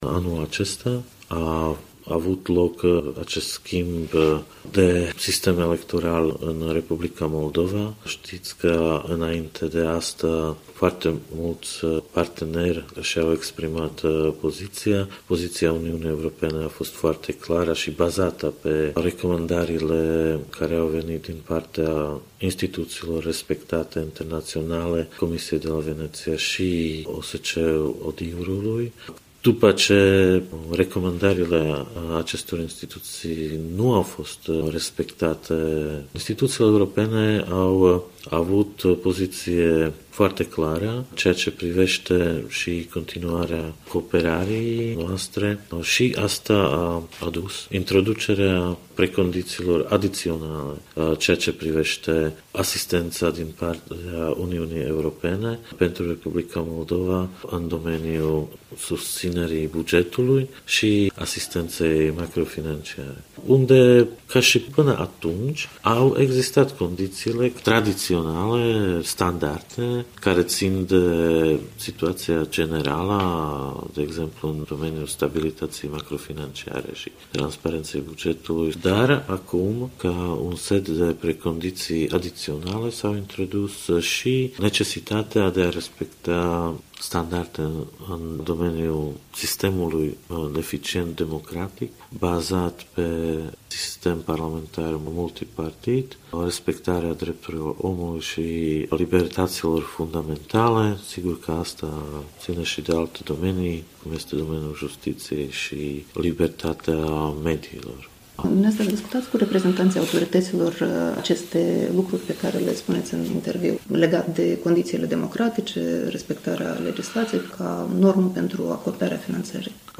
Un interviu în exclusivitate cu șeful Delegației permanente a UE la Chișinău.